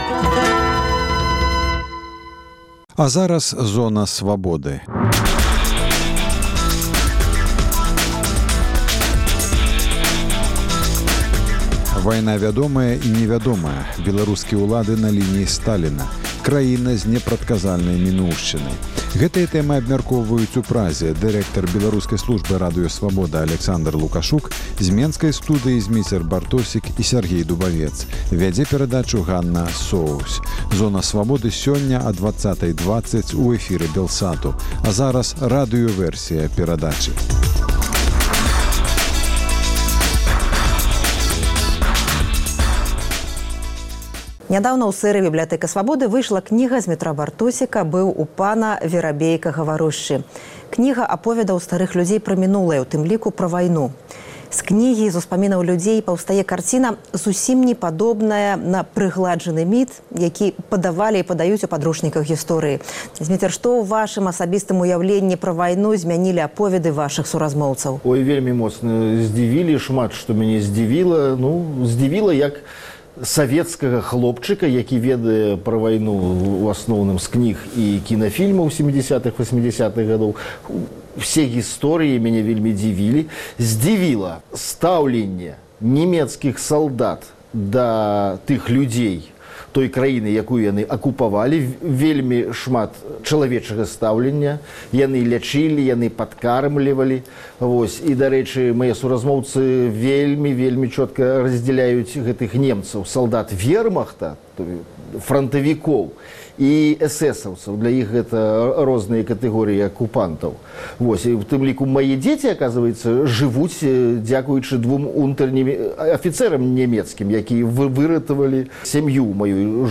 зь менскай студыі